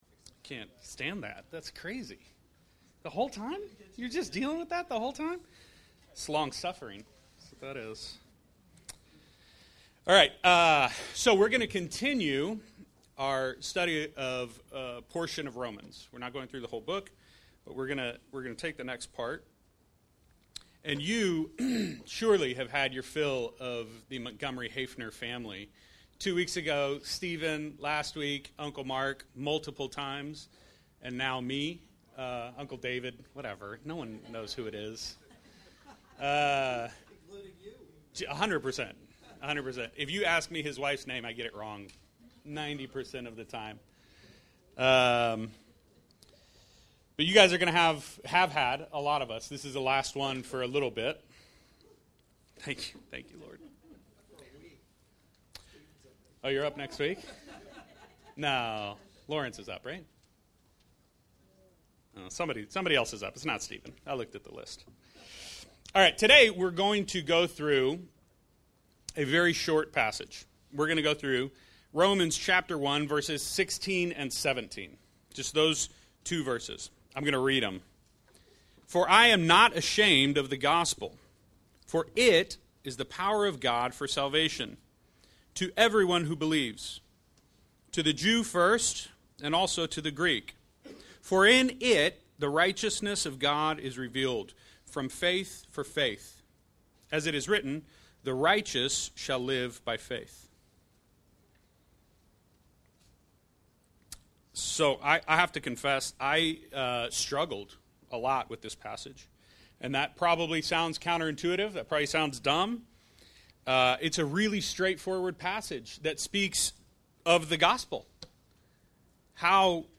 Romans 1:16-17 Service Type: Sunday Morning « Romans